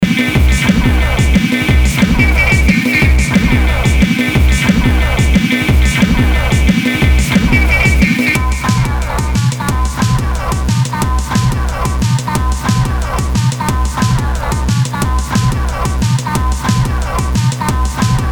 Work in 2 hours, clearing a time, for a track therefore, splice and cardio at 90bpm.